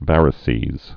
(vărĭ-sēz)